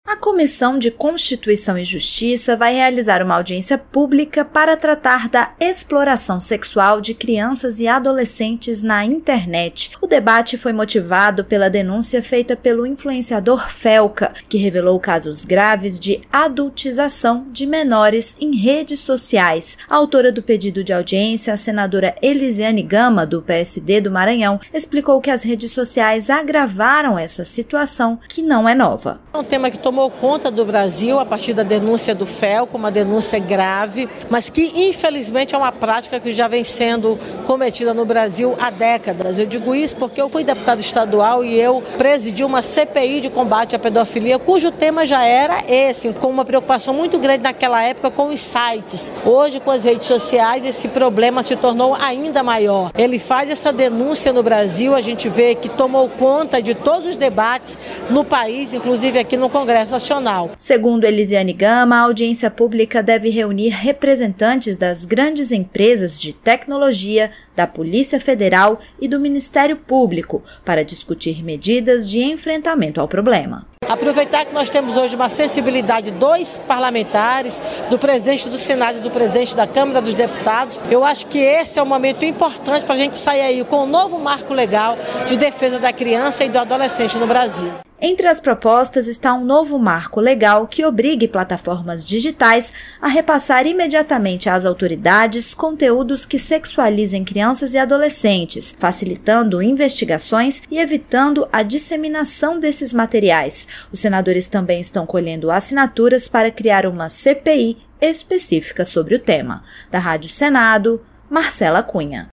A Comissão de Constituição e Justiça (CCJ) fará uma audiência pública para discutir a sexualização de crianças na internet, após casos expostos pelo influenciador Felipe Bressanim, o Felca. A autora do requerimento (REQ 20/2025 - CCJ), senadora Eliziane Gama (PSD-MA), explicou que representantes das empresas de tecnologia, da Polícia Federal e do Ministério Público vão participar do debate, que pode resultar em novo marco legal e na criação de uma CPI sobre o tema.